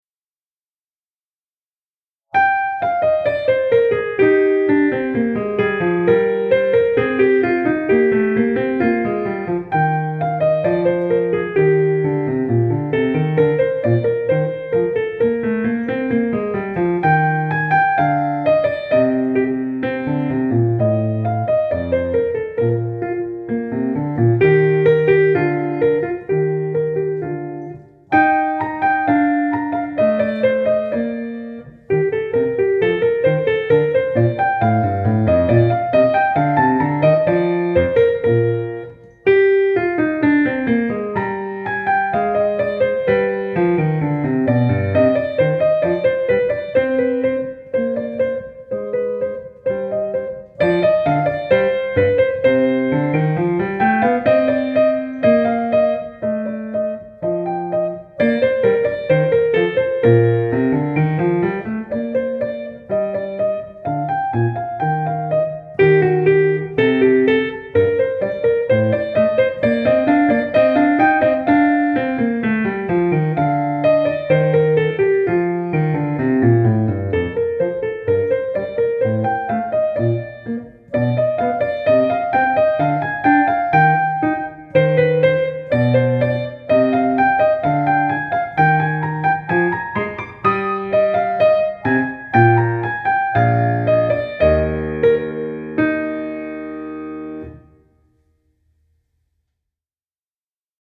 Succeed with the quick study sight reading section of your piano diploma exam.